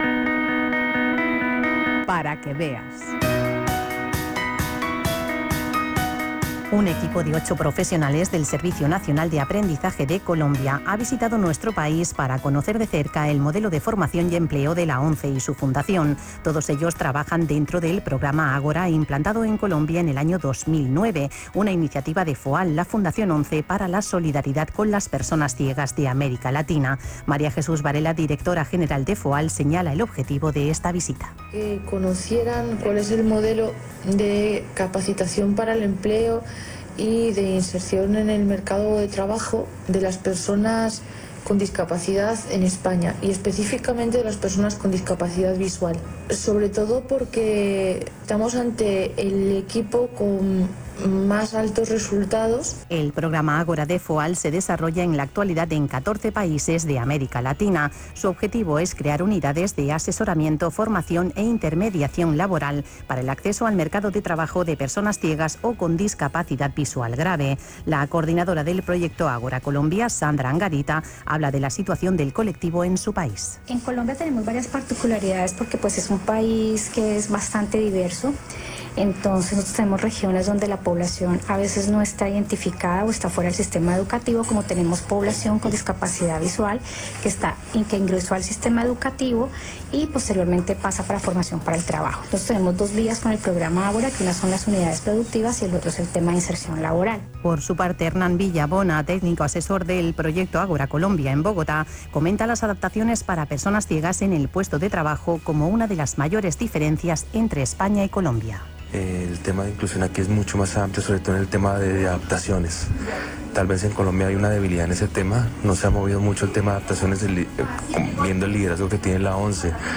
Crónica de la visita emitida por Radio 5 (archivo mp3)